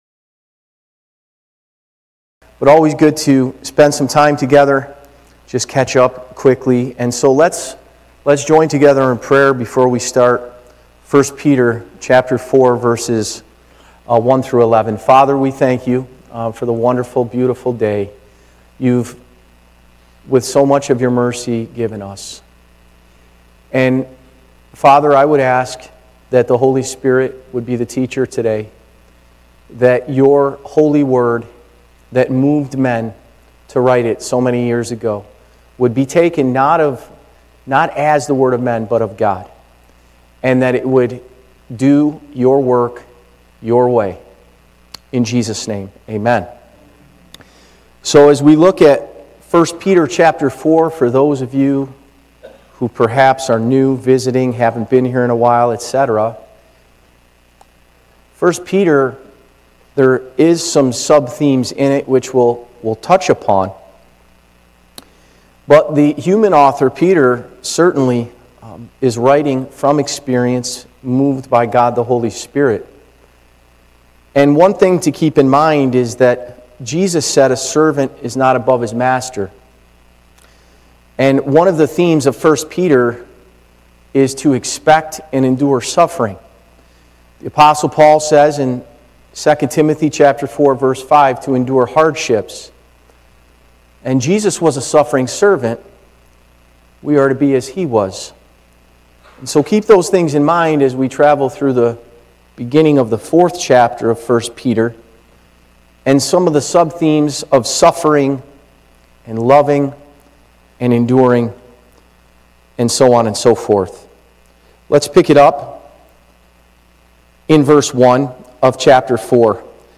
Church Location: Spencerport Bible Church
God’s Work, God’s Way | 1 Peter 4:1-11 Live Recording